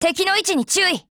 贡献 ） 协议：可自由使用，其他分类： 分类:SCAR-H 、 分类:语音 您不可以覆盖此文件。
SCARH_DEFENSE_JP.wav